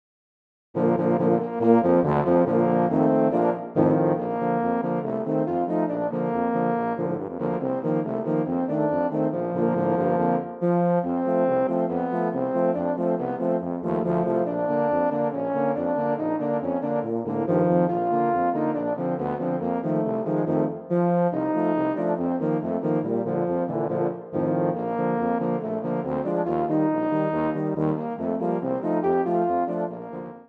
Voicing: Tuba Quartet (EETT)